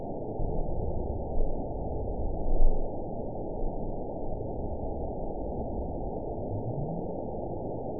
event 911442 date 02/28/22 time 18:19:19 GMT (3 years, 2 months ago) score 9.61 location TSS-AB02 detected by nrw target species NRW annotations +NRW Spectrogram: Frequency (kHz) vs. Time (s) audio not available .wav